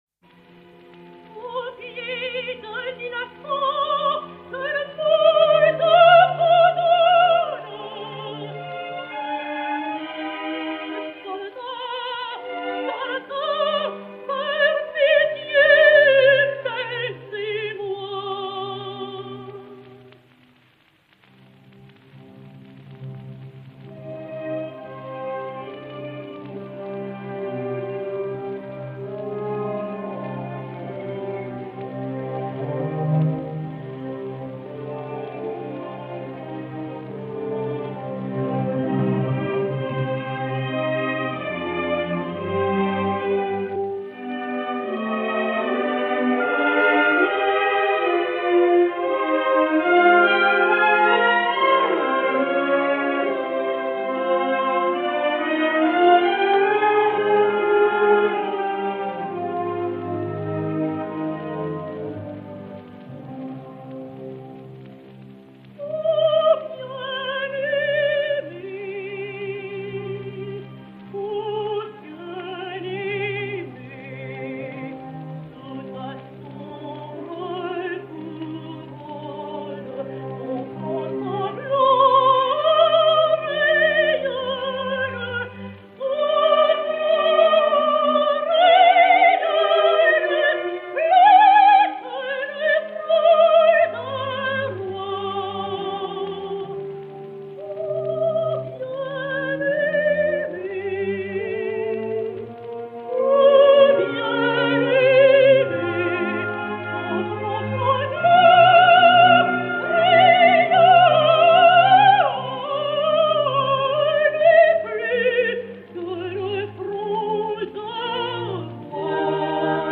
Marguerite Mérentié (Méryem) et Orchestre